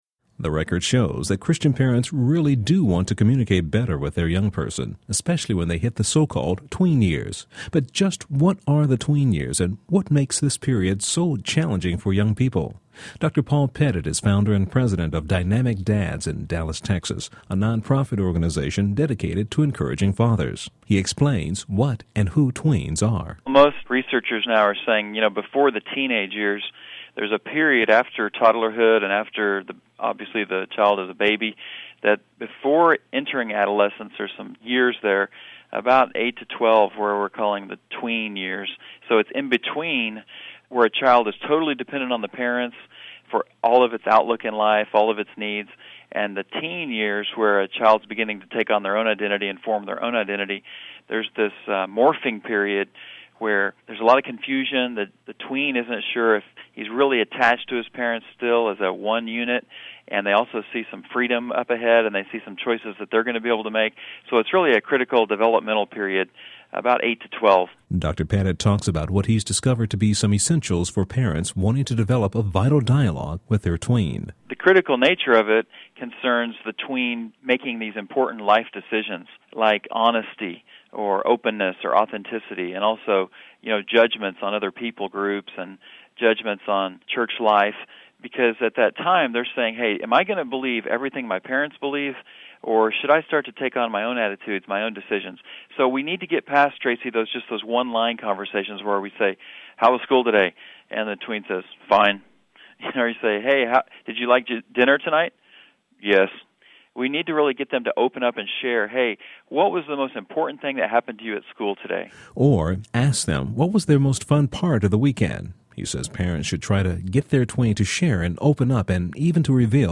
Dynamic Dads on Prime Time America - Moody Radio